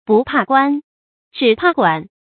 注音：ㄅㄨˋ ㄆㄚˋ ㄍㄨㄢ ，ㄓㄧˇ ㄆㄚˋ ㄍㄨㄢˇ
不怕官，只怕管的讀法